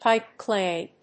アクセントpípe clày